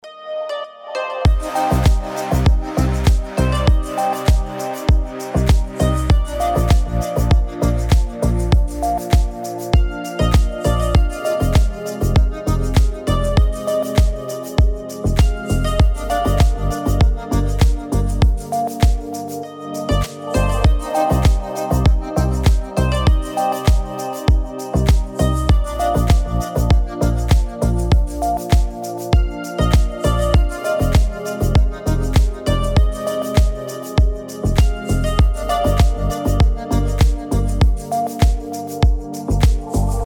без слов
романтичные
красивая музыка
Романтичная музыка